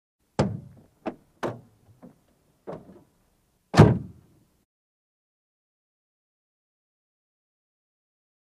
Mercedes-Benz|Hood O/C | Sneak On The Lot
Car Hood Open And Close; Mercedes, Close Perspective.